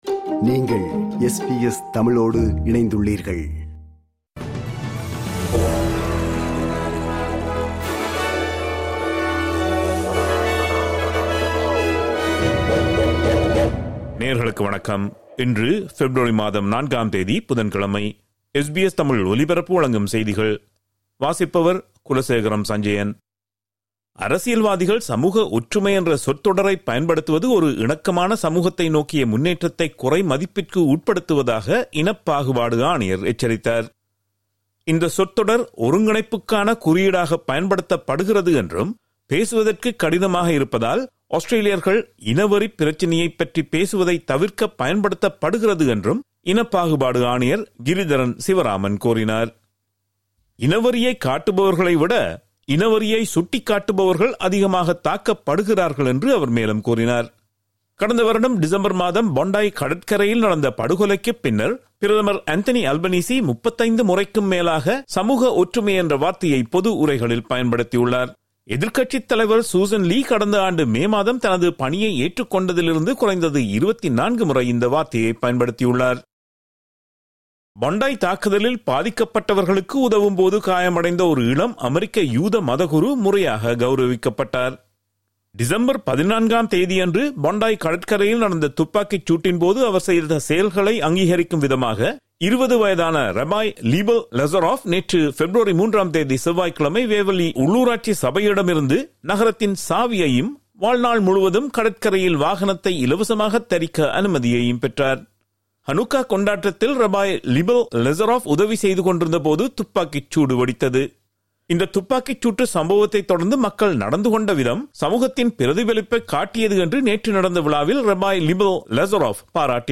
SBS தமிழ் ஒலிபரப்பின் இன்றைய (புதன்கிழமை 04/02/2026) செய்திகள்.